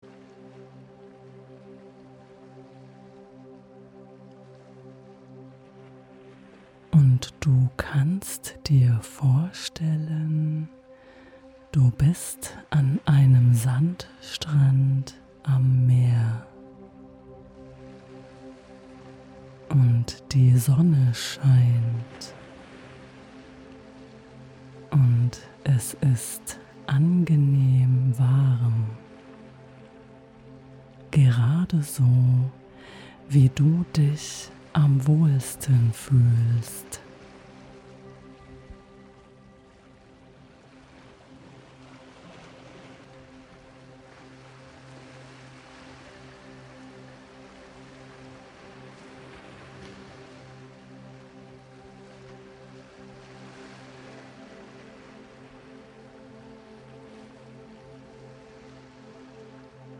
Dies ist eine geführte Fantasiereise, die dich an einen sonnigen, friedlichen Strand führt – ein Ort der Ruhe, der Wärme und der Weite.